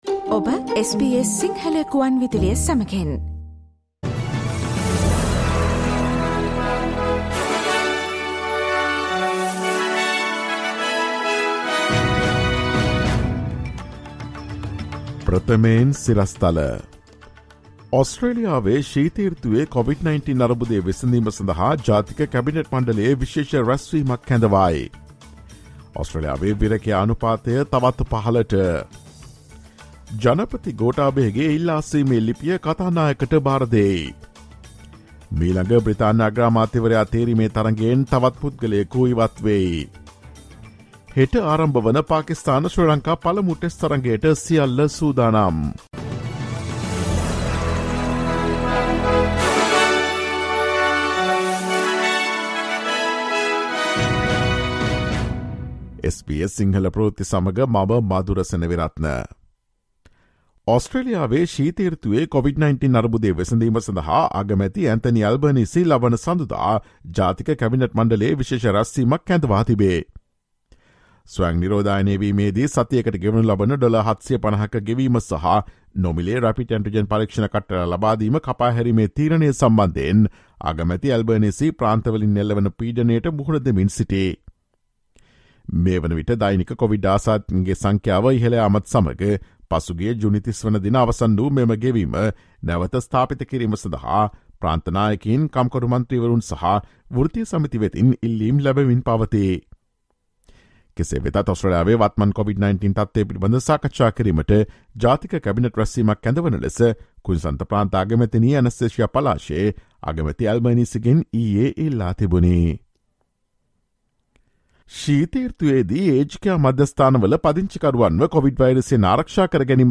ඔස්ට්‍රේලියාවේ සහ ශ්‍රී ලංකාවේ නවතම පුවත් මෙන්ම විදෙස් පුවත් සහ ක්‍රීඩා පුවත් රැගත් SBS සිංහල සේවයේ 2022 ජූලි 15 වන දා සිකුරාදා වැඩසටහනේ ප්‍රවෘත්ති ප්‍රකාශයට සවන් දීමට ඉහත ඡායාරූපය මත ඇති speaker සලකුණ මත click කරන්න.